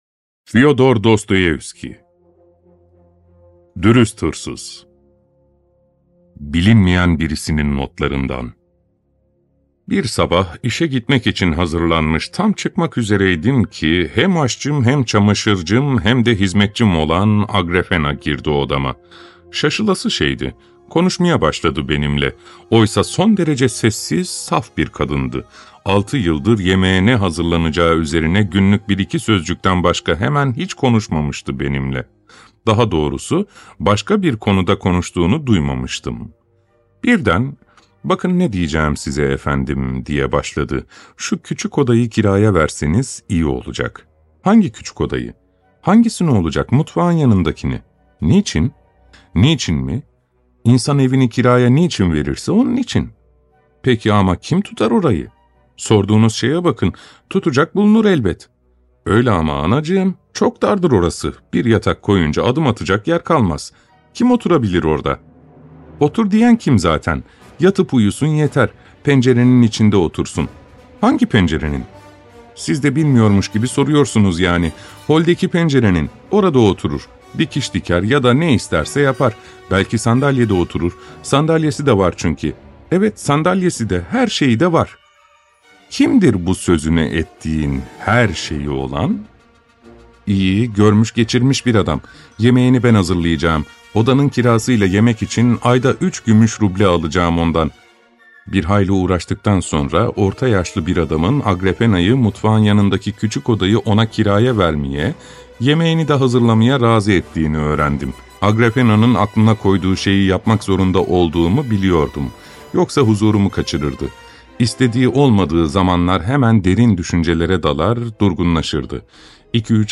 Dürüst Hırsız – Dostoyevski | Sesli Kitap – Podcast Dİnle